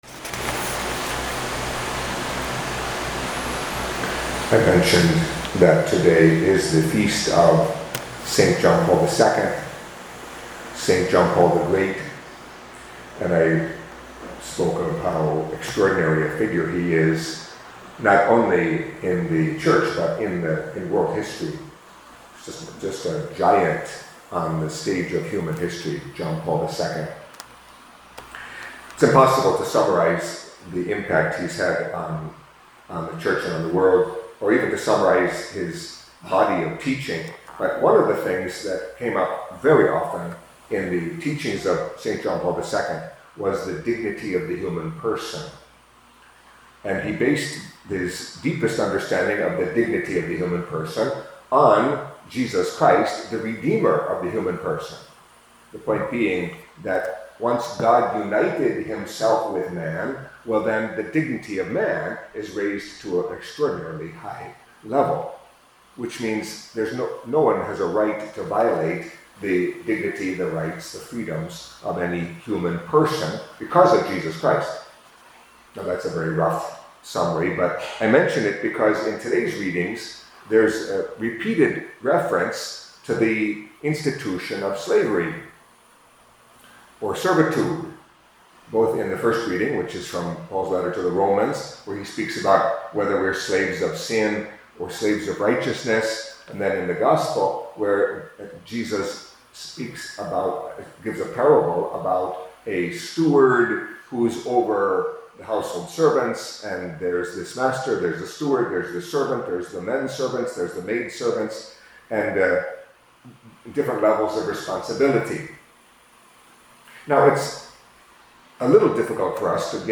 Catholic Mass homily for Wednesday of the Twenty-Ninth Week in Ordinary Time